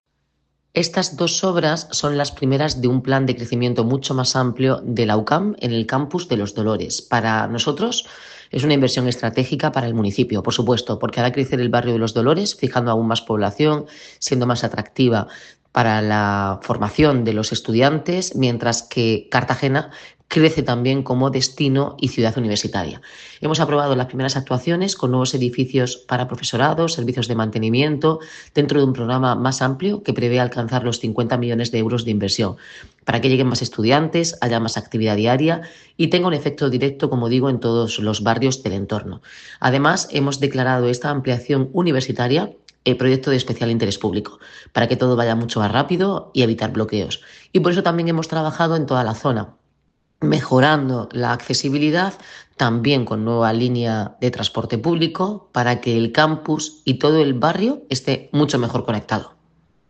Enlace a Declaraciones de la alcaldesa.